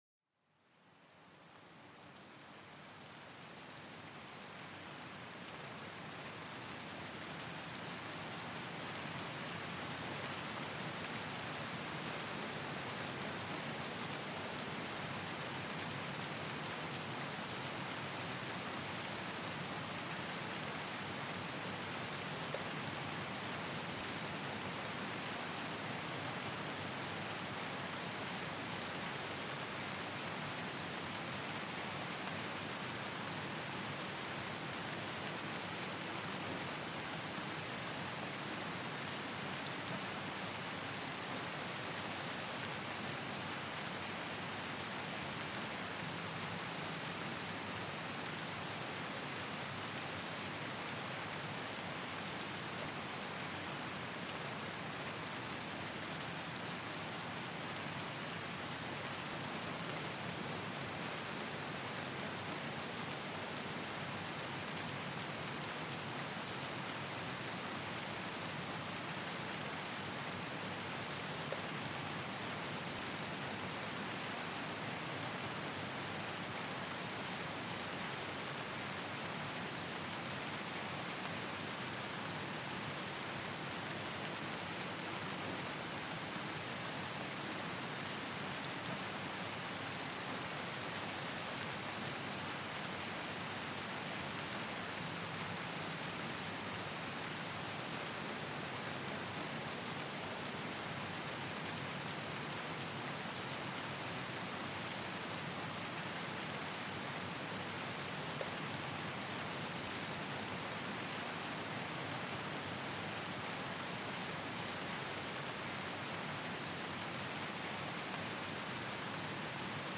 Pluie Apaisante pour une Relaxation Profonde
Écoutez le doux bruit des gouttes de pluie qui tombent lentement, créant une ambiance paisible. Chaque son vous enveloppe dans une atmosphère réconfortante, parfaite pour calmer l'esprit.